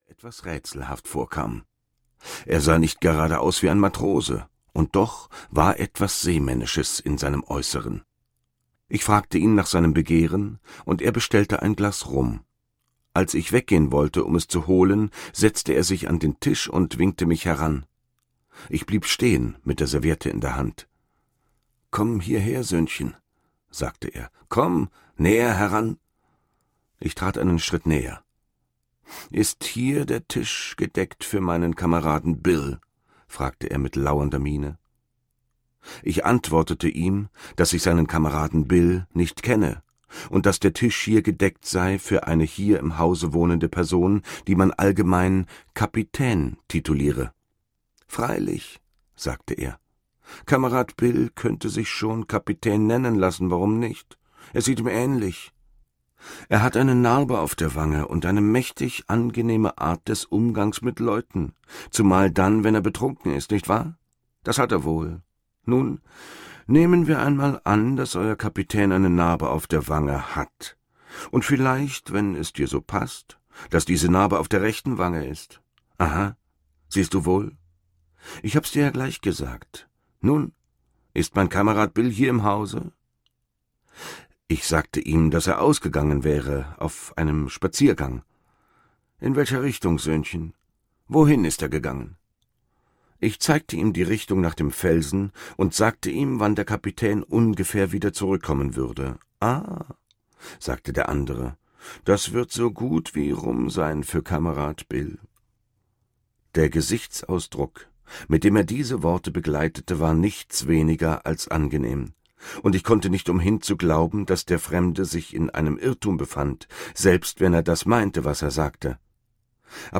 Die Schatzinsel - Robert Louis Stevenson - Hörbuch